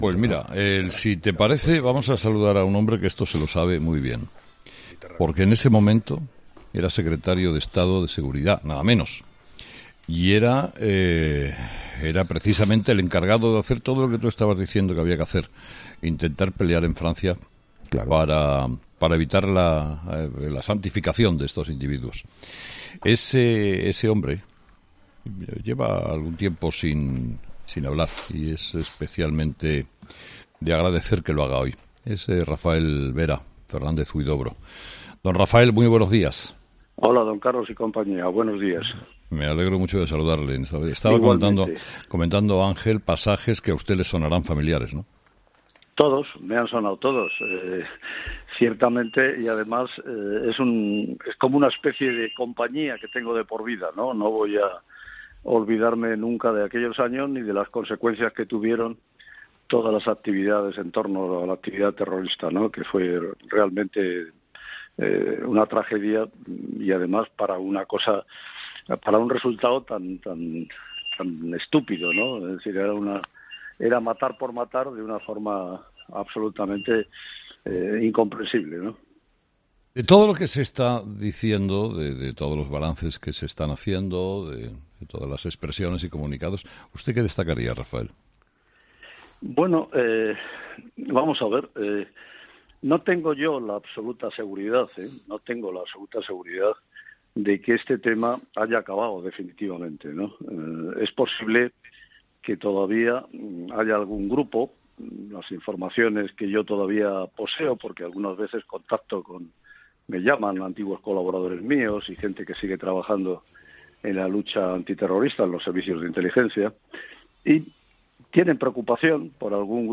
Entrevista a Rafael Vera, exsecretario de Estado de Seguridad